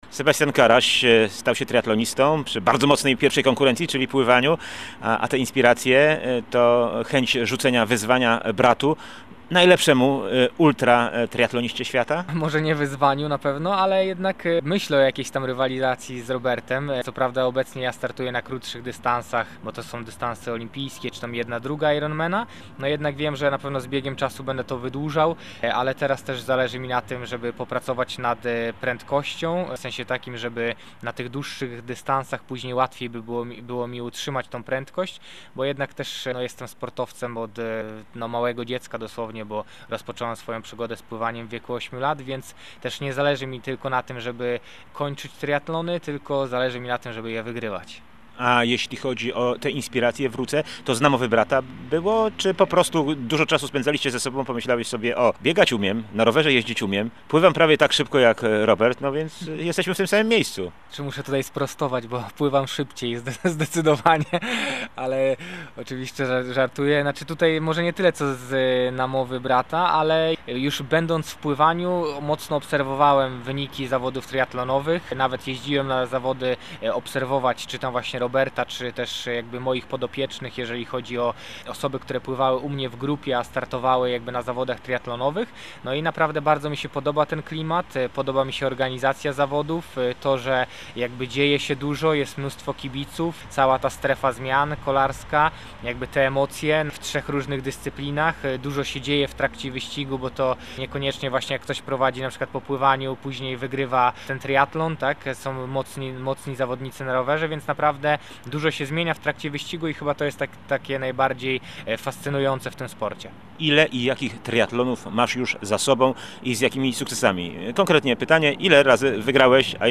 Zapraszamy na drugi odcinek rozmowy